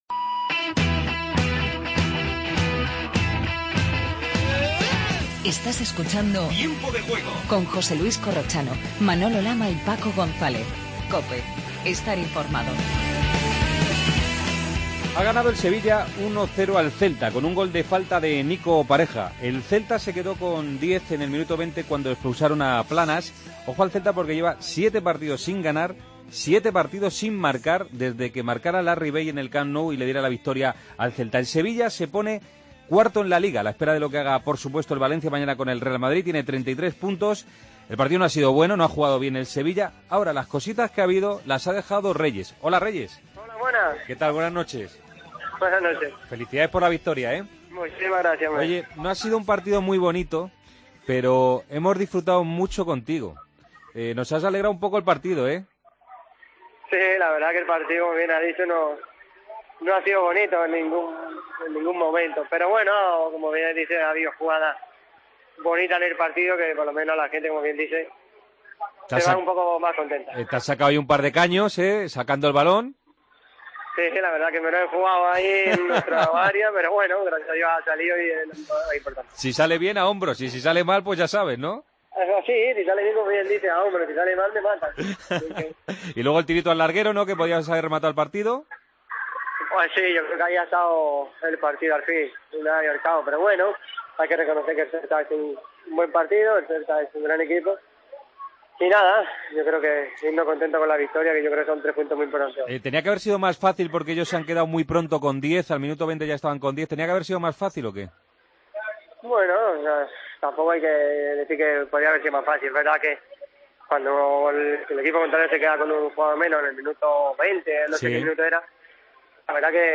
Redacción digital Madrid - Publicado el 04 ene 2015, 01:41 - Actualizado 14 mar 2023, 00:27 1 min lectura Descargar Facebook Twitter Whatsapp Telegram Enviar por email Copiar enlace Resto de la jornada de este sábado en la Liga BBVA. Entrevistas a Reyes, jugador del Sevilla, y Asenjo, portero del Villarreal. Arranca el Dakar 2015 en Buenos Aires.